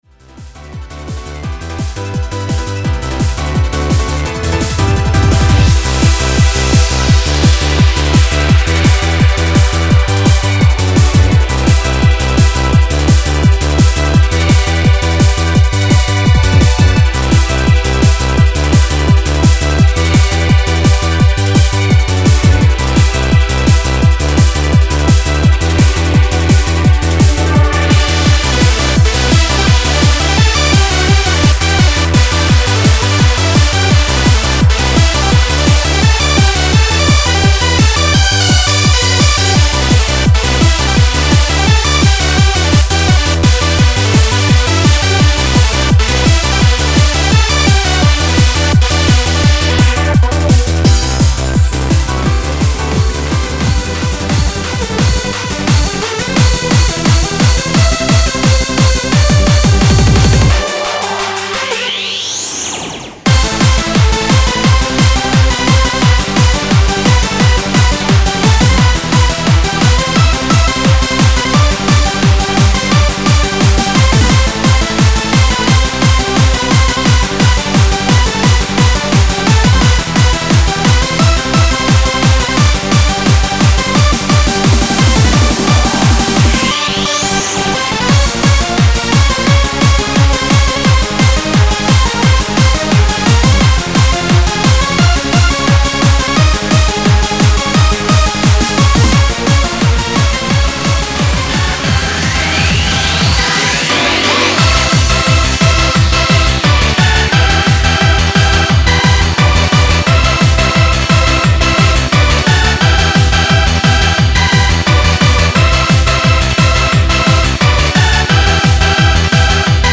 [Crossfade Demo]